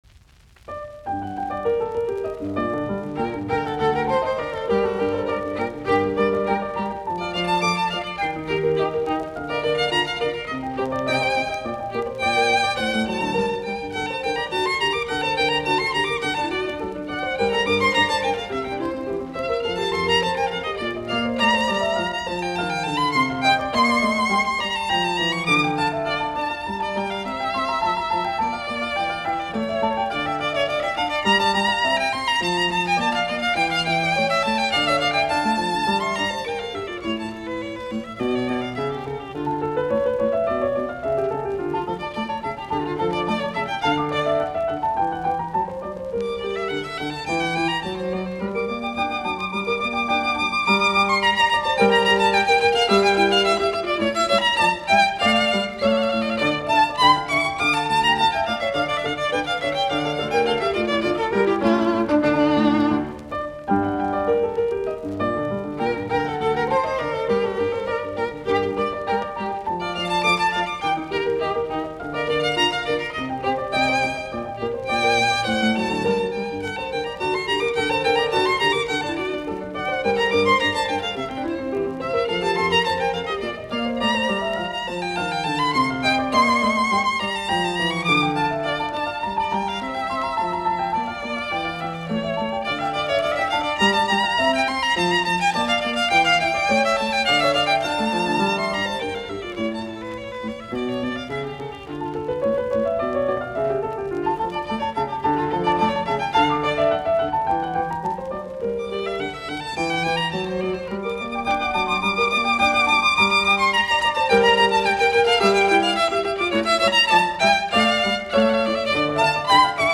Soitinnus : Viulu, piano